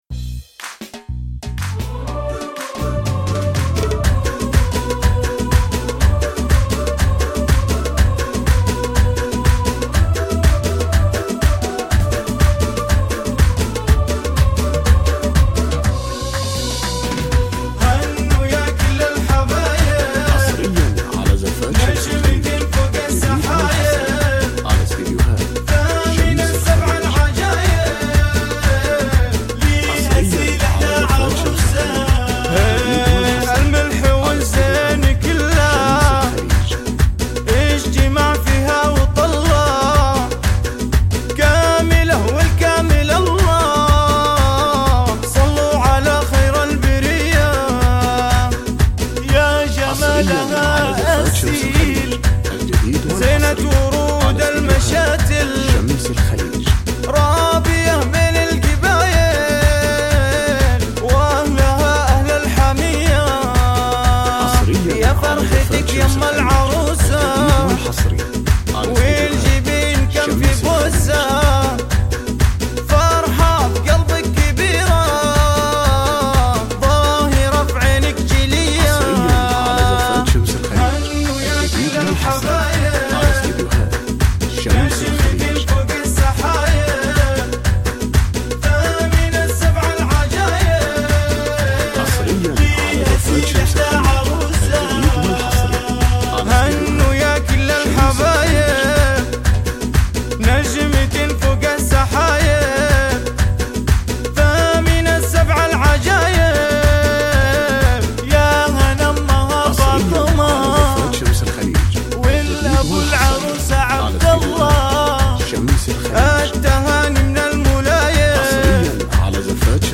زفات موسيقى بدون اسماء